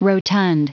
Prononciation du mot rotund en anglais (fichier audio)
Prononciation du mot : rotund